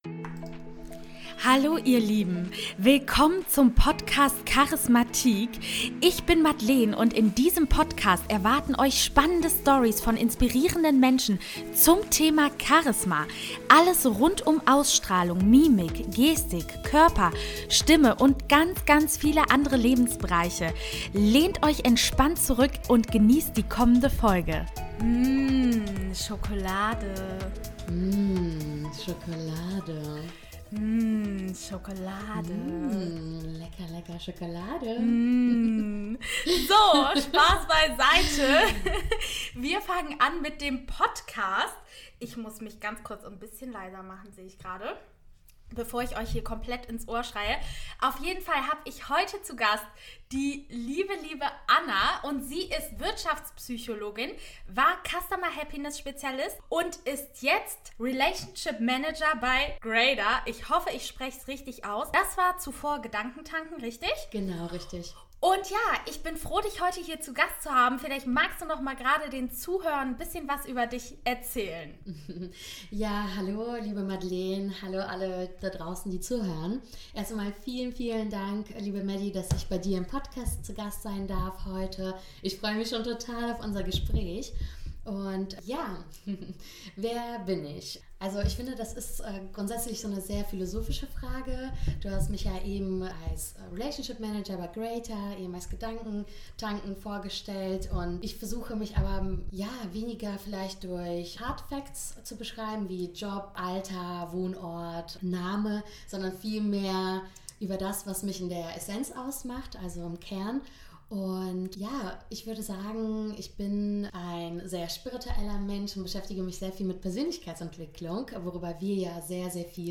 Fühle und spüre die Person, die du sein willst 24/7 -Interview